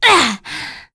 Demia-Vox_Attack2.wav